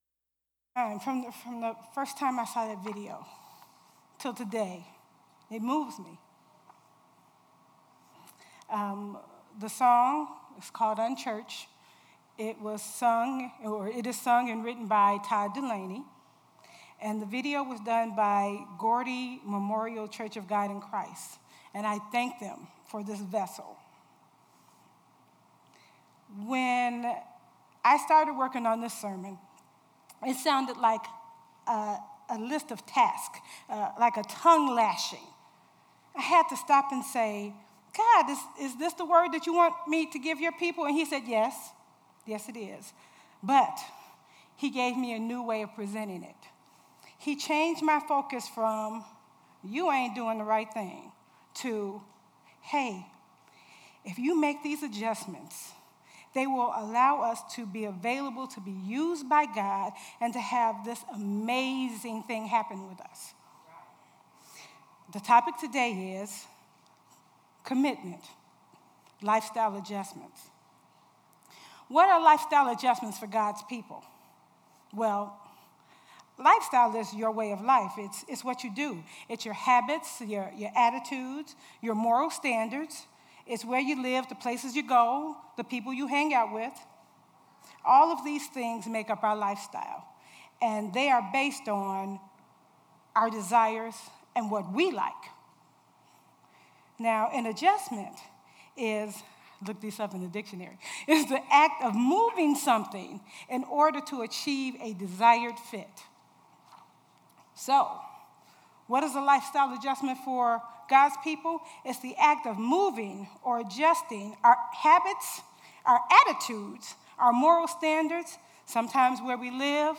Worship Service 8/20/17